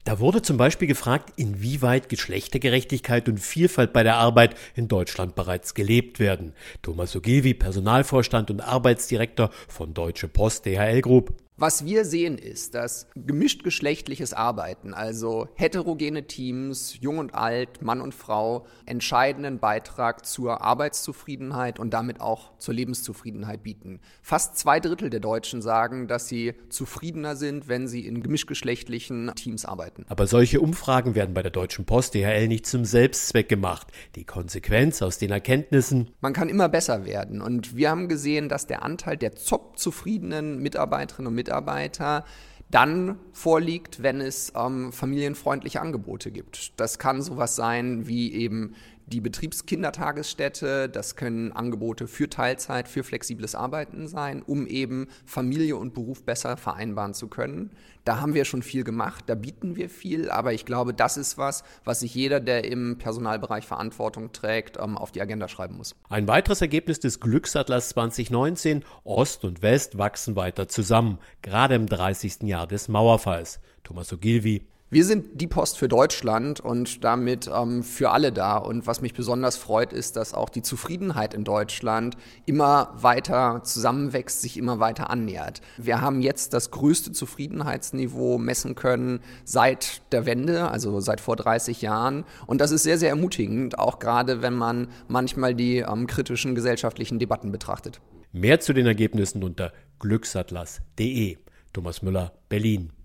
O-Töne, Interview und Magazin: Glücksatlas 2019 – Vorabs Medienproduktion